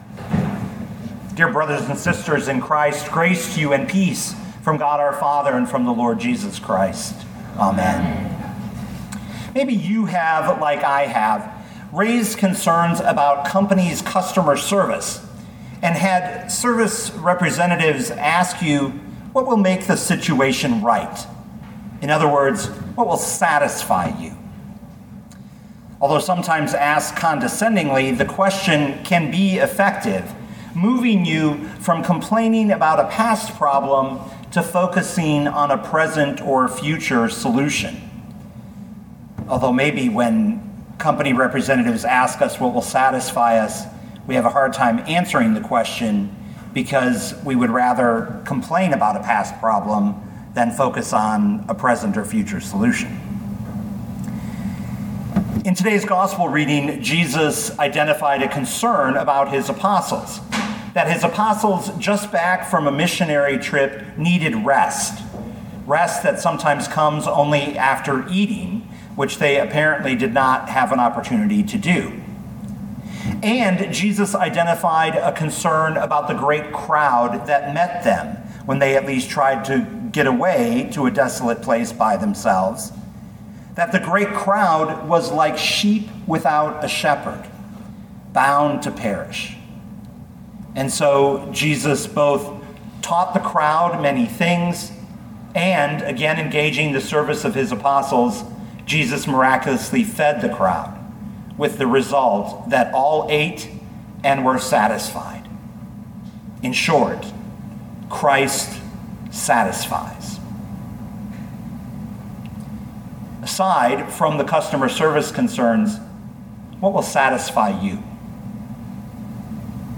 2021 Mark 6:30-44 Listen to the sermon with the player below, or, download the audio.